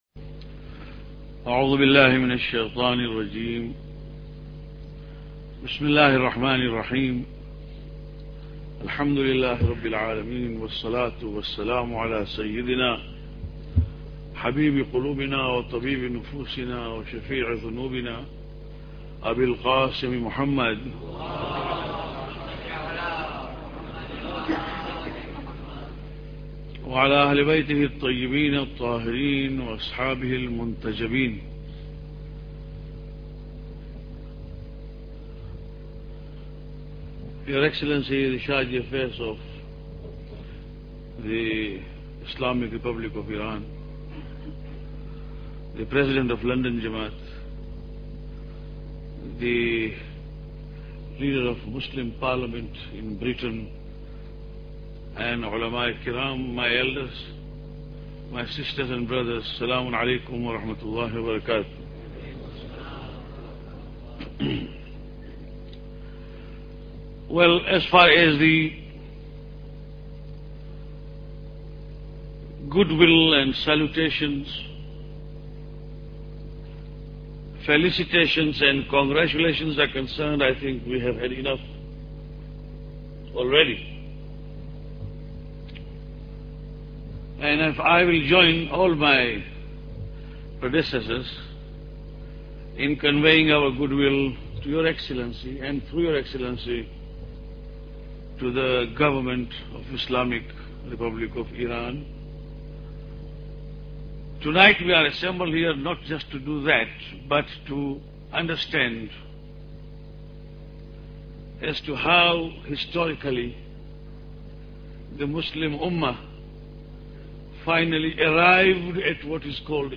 Lecture 5